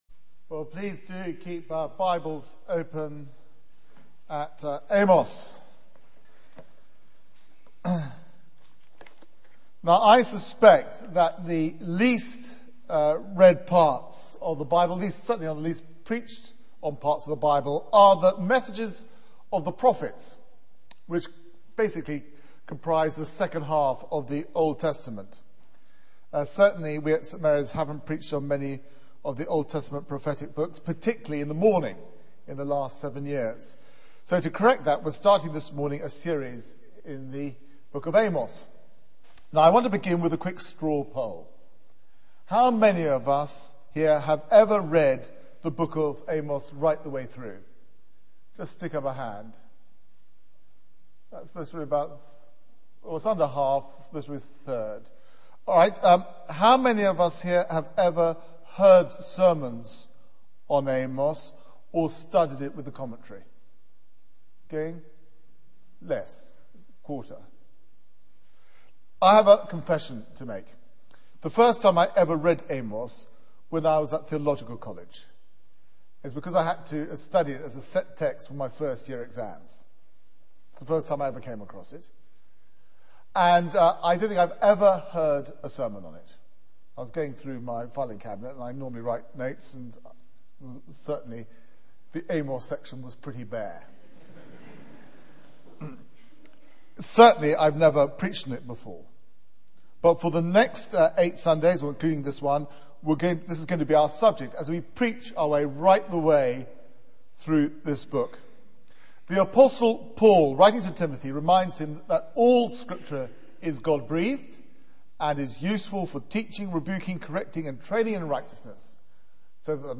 Media for 9:15am Service on Sun 03rd Jan 2010 09:15 Speaker: Passage: Amos 1:1-2:16 Series: Amos Theme: The Whirlpool of Judgement There is private media available for this event, please log in. Sermon Search the media library There are recordings here going back several years.